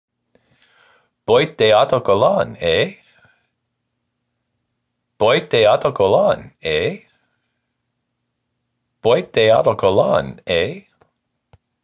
French-Canadian